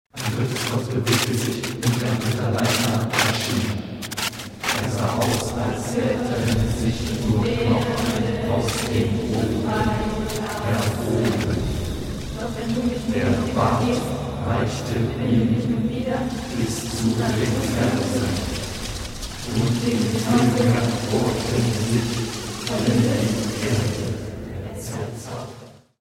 Das Imaginäre wird visuell durch eine dreifache Videoproduktion hergestellt, auf drei Ebenen, zeitlich versetzt und polyphonisch geordnet; akustisch wird der Raum in zwei Kreise geteilt, was der Teilung ins Reale und Irreale entspricht.